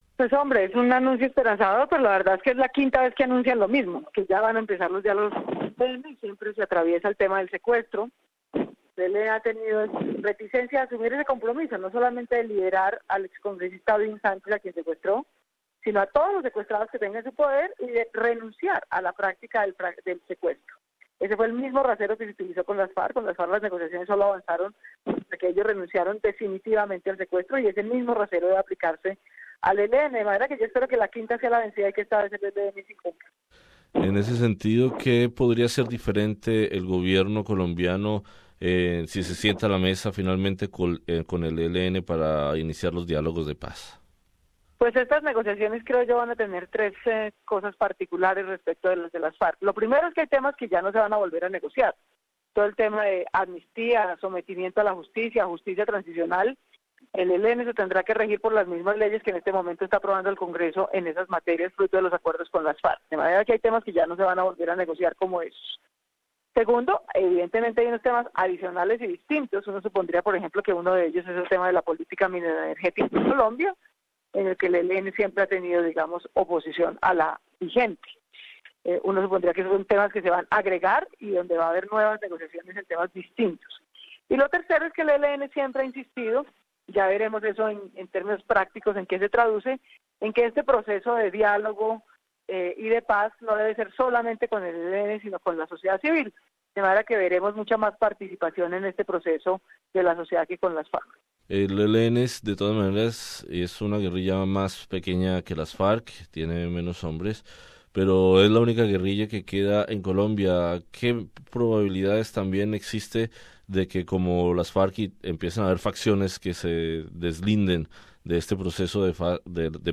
Conversamos con la senadora del partido Alianza Verde, Claudia Lopez.